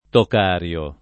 tocario [ tok # r L o ]